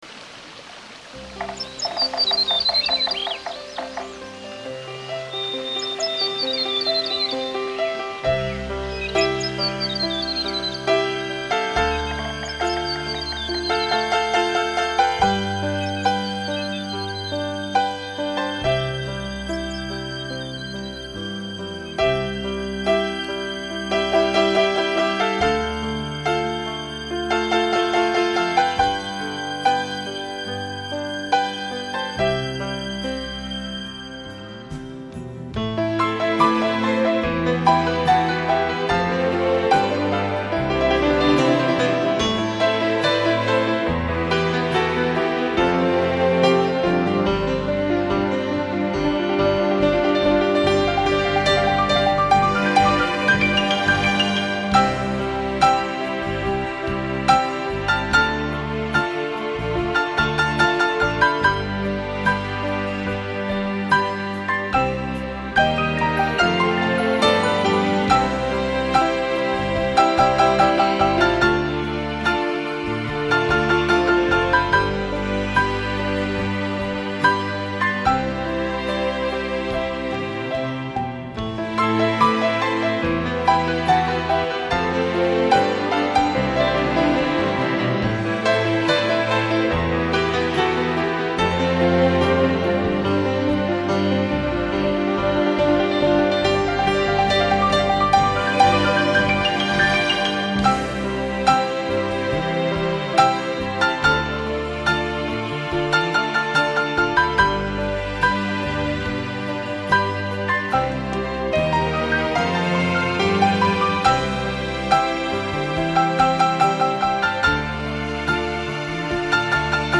音乐类别: 纯音乐,New Age, Various